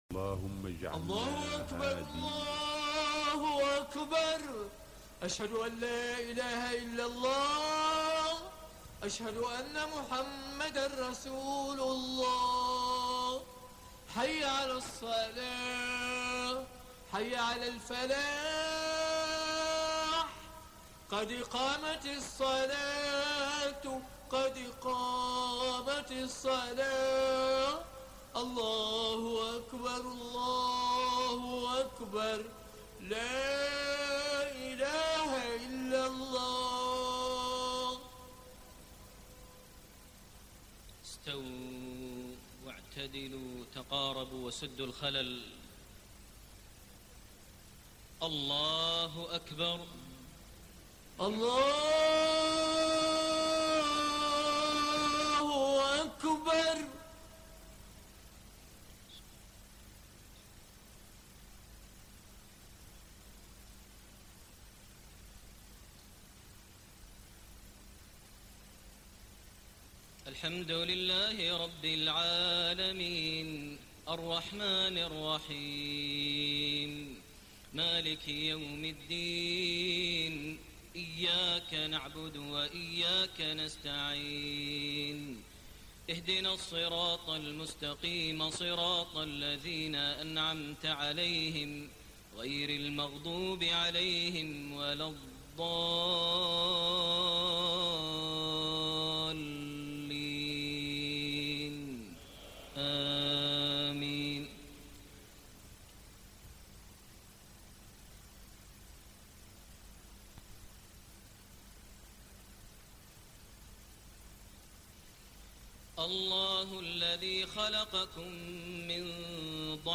Maghrib prayer from Surat Ar-Room > 1428 H > Prayers - Maher Almuaiqly Recitations